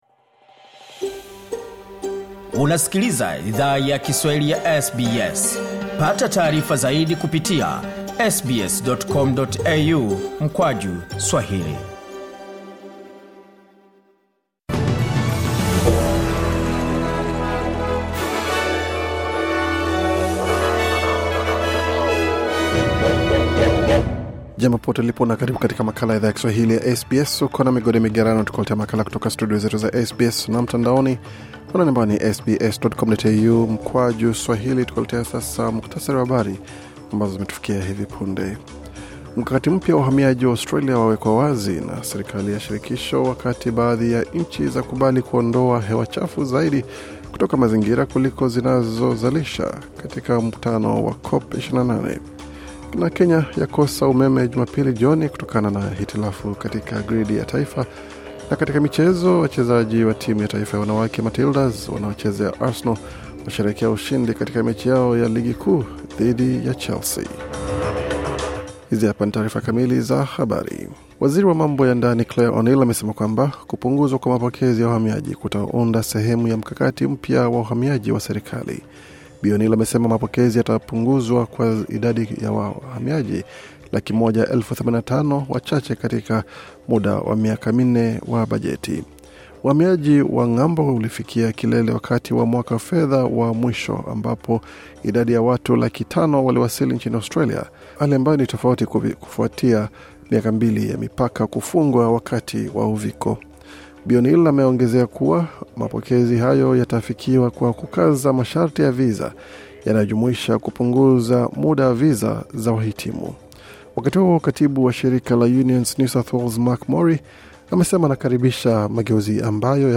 Taarifa ya Habari 11 Disemba 2023